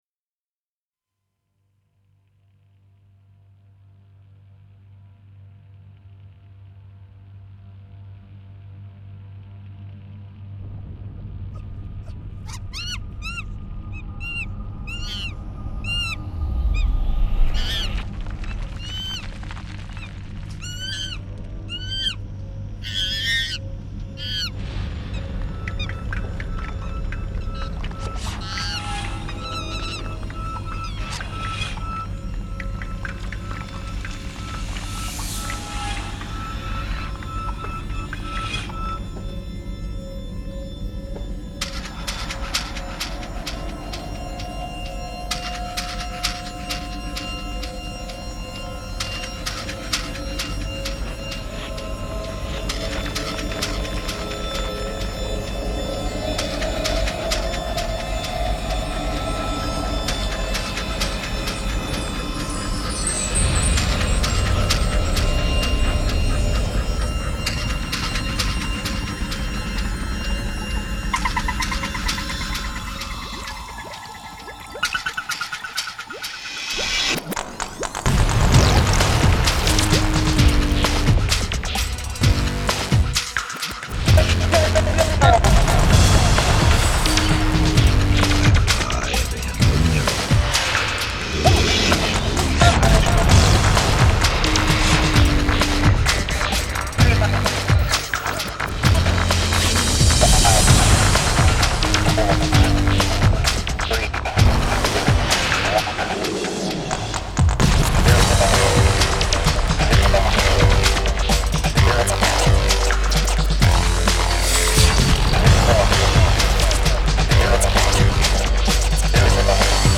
pure sensitive electronic music
Grinding sounds straight out from the boring hell of europe.